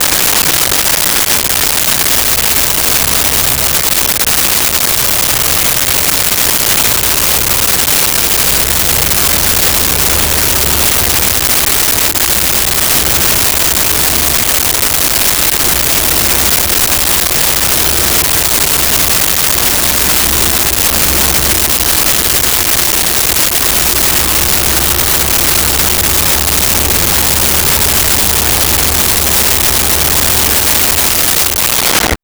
Prop Plane Overhead
Prop Plane Overhead.wav